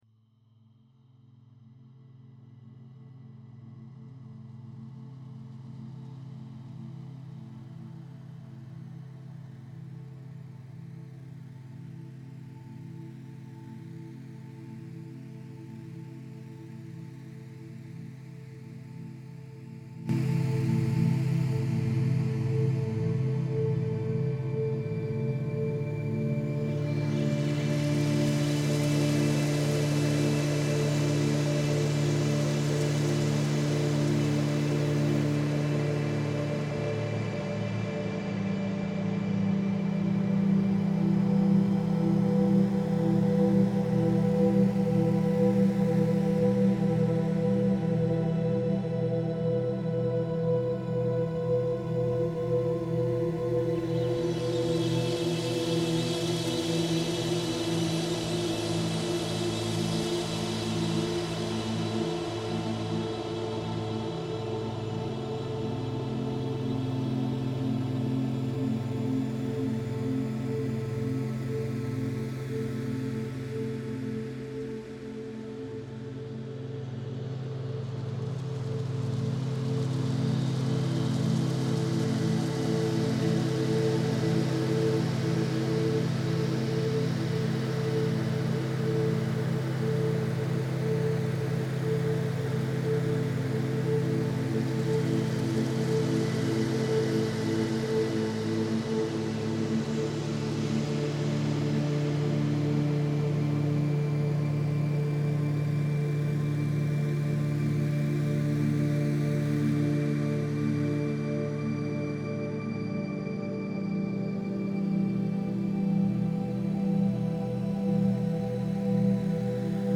The audio jumps in volume at 20 seconds in.
1 min read # music # ambient An older download from 2025 WARNING The audio jumps in volume at 20 seconds in.